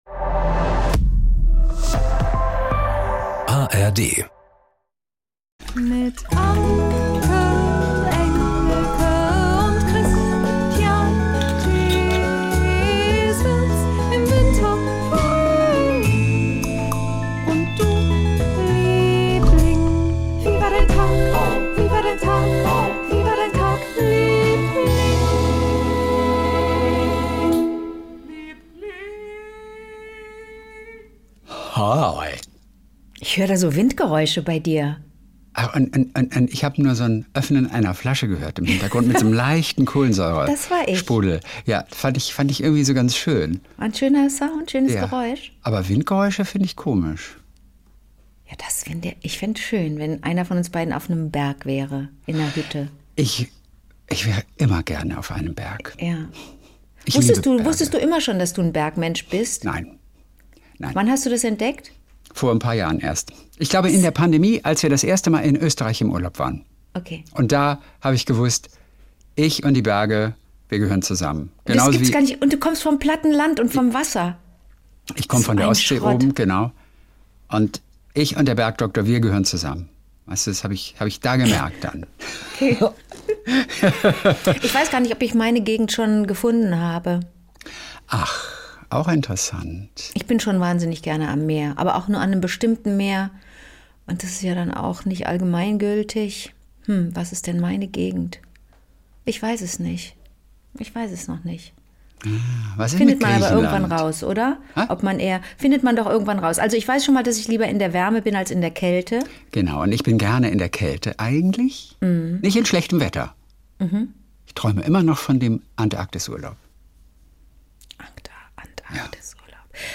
1 KW 4 (Mo) Gewürzgurke oder Krokodil ? 58:03 Play Pause 2h ago 58:03 Play Pause Später Spielen Später Spielen Listen Gefällt mir Geliked 58:03 Jeden Montag und Donnerstag Kult: SWR3-Moderator Kristian Thees ruft seine beste Freundin Anke Engelke an und die beiden erzählen sich gegenseitig ihre kleinen Geschichtchen des Tages.